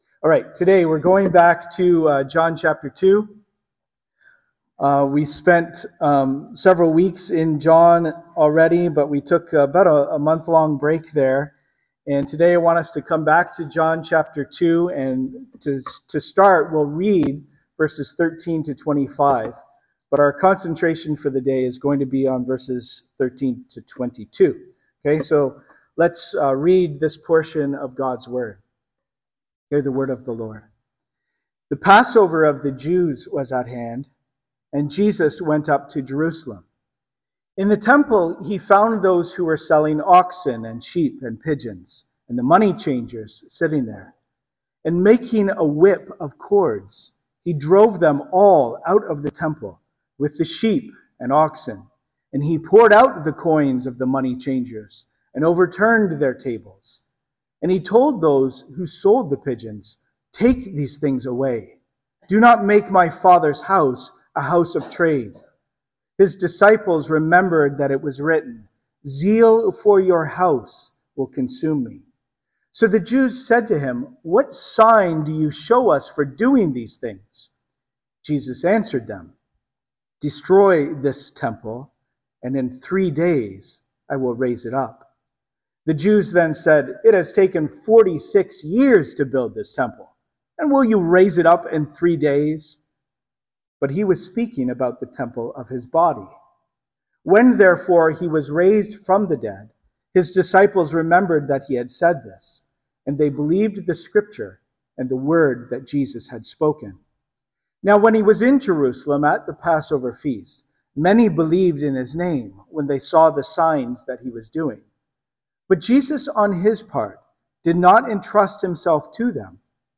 Sermons - Cannington Baptist Church